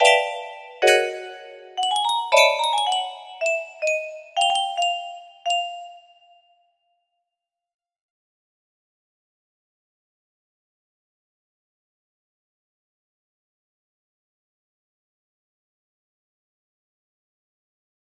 Unknown Artist - up down music box melody